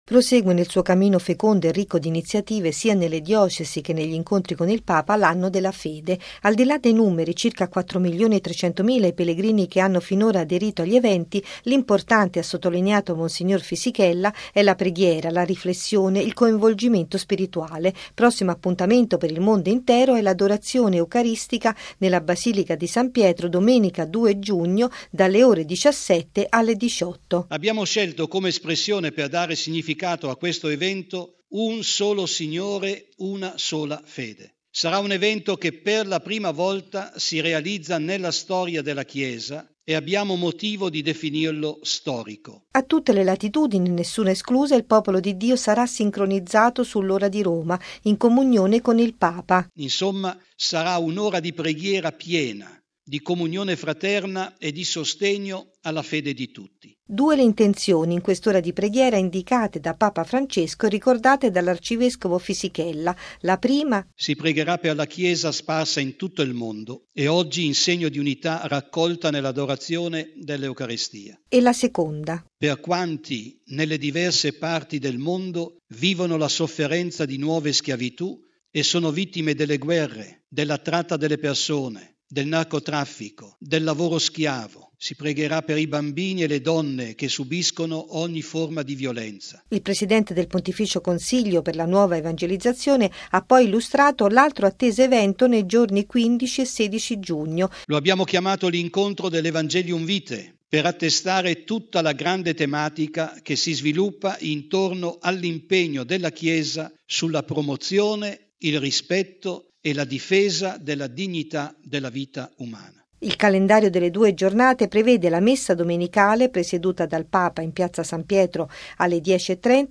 ◊   Incontro con i giornalisti stamani nella Sala Stampa vaticana per illustrare i prossimi appuntamenti nell’Anno della Fede: la “Solenne Adorazione Eucaristica in contemporanea mondiale”, il 2 giugno, e la Giornata dell’Evangelium Vitae, il 15 e 16 giugno.